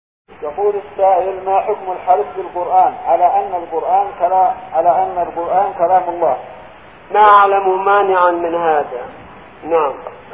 --------------- من شريط : ( أسئلة محاضرة مسجد العيسائي بتعز )